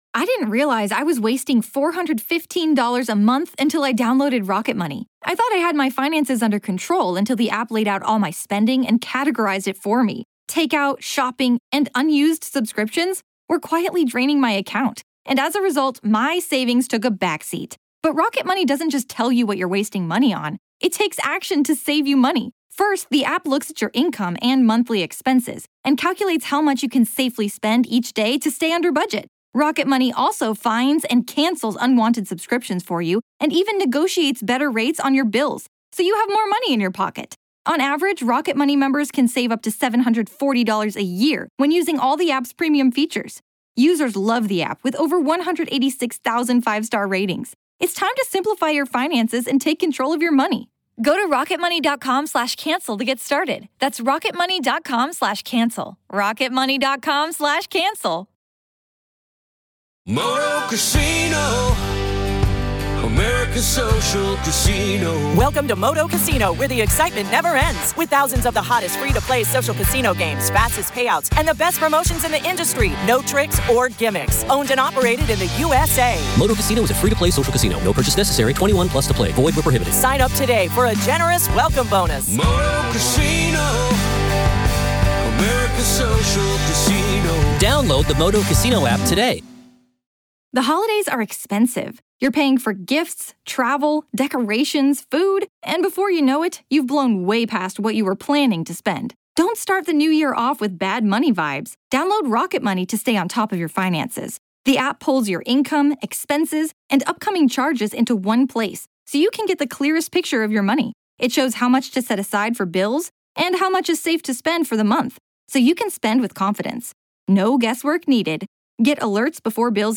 This is a conversation about trauma, spiritual warfare, and what it takes to reclaim your life when everything seems determined to pull you under.